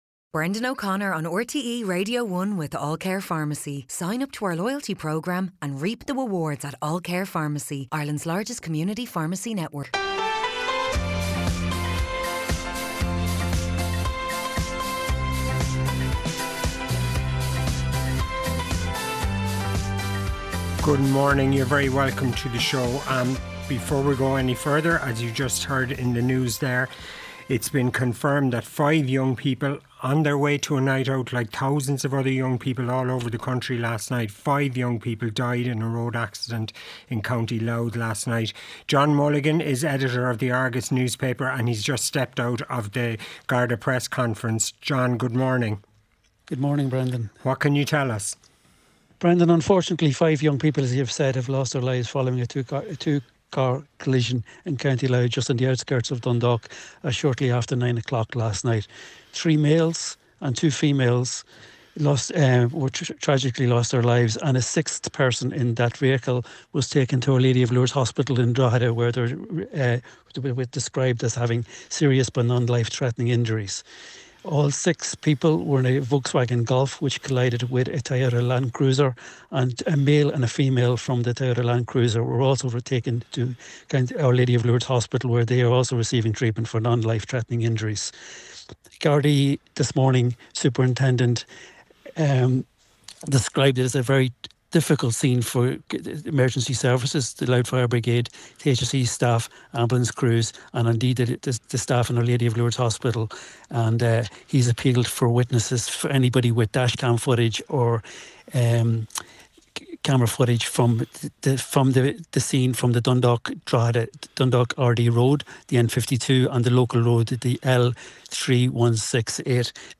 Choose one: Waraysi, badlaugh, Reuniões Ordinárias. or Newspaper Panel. Newspaper Panel